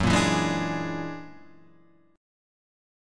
F#+9 chord {2 1 2 1 3 4} chord